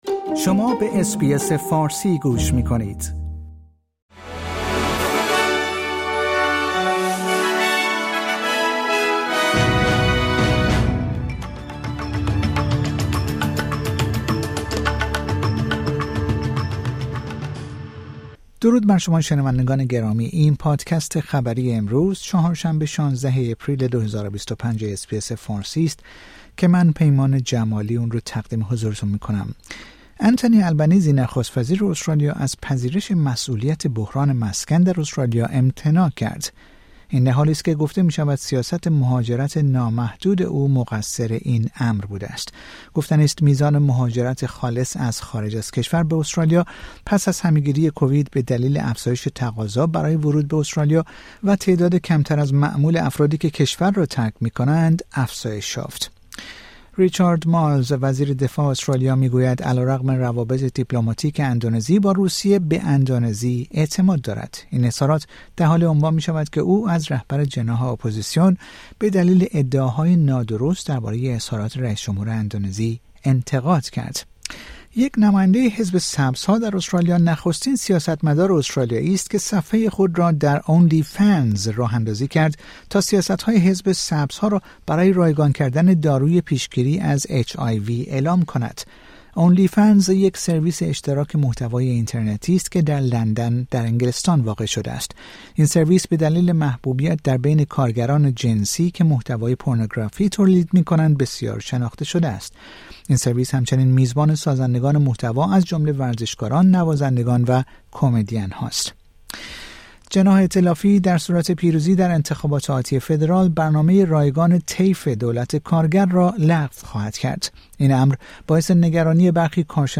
در این پادکست خبری مهمترین اخبار امروز چهارشنبه ۱۶ آپریل ارائه شده است.